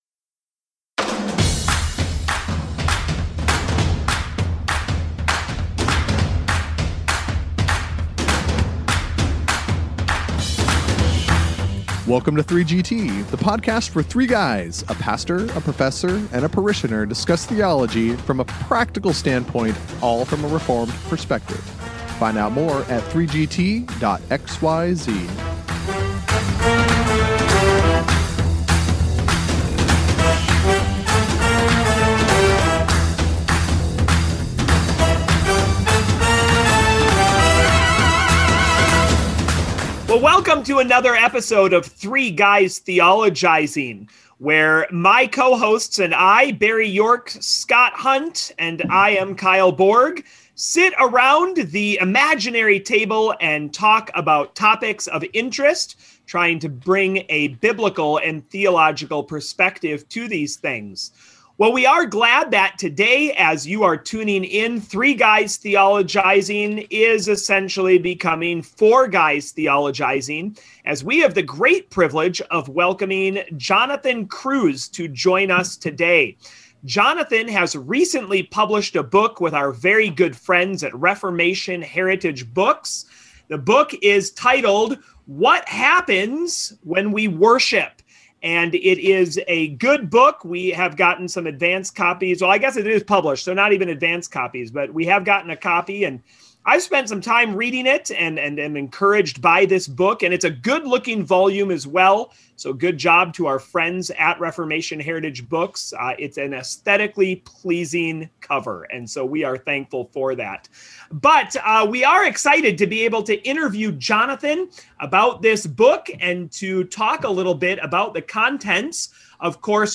Topics such as spiritual formation, covenant renewal, God’s agenda, and confession of sin are addressed. The importance of prioritizing public worship, especially in light of the Corona epidemic, is stressed. It’s a lively discussion about the most important act God’s people can do!